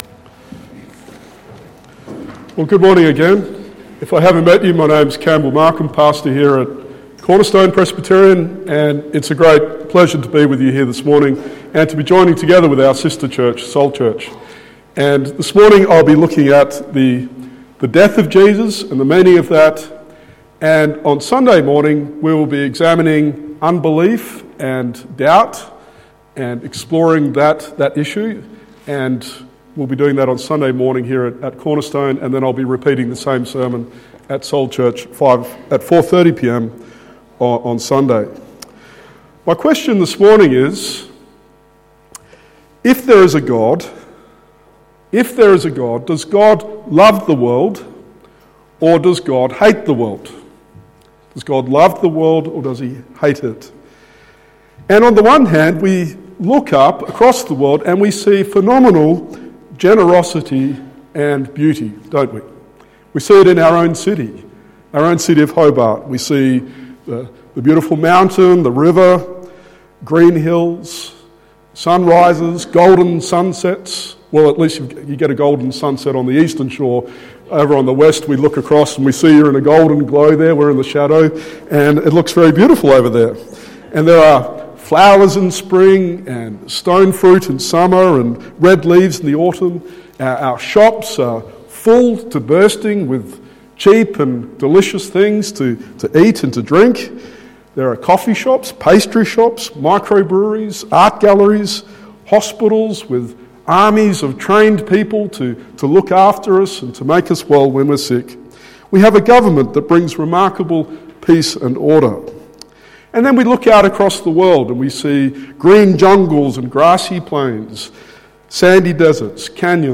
John 3:16-17 Sermon